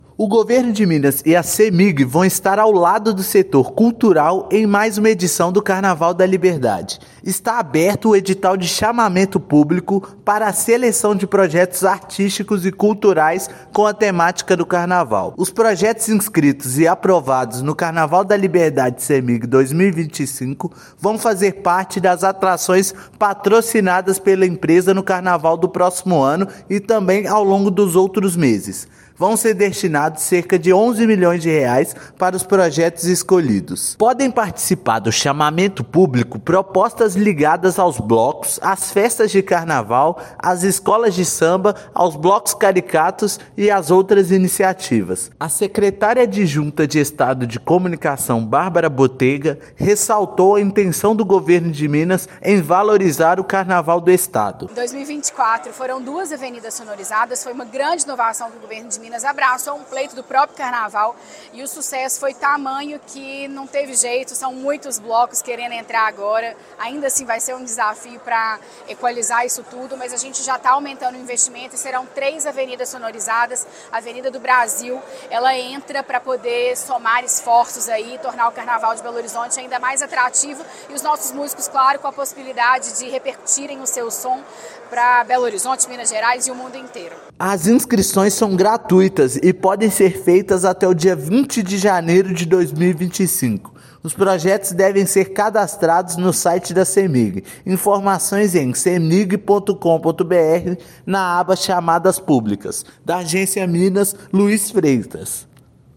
Propostas aprovadas ficarão em um banco de projetos e poderão ser incentivadas ao longo de 2025. Ouça matéria de rádio.
Rádio_Matéria_Carnaval_da_Liberdade_2025.mp3